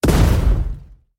cannonFire.ogg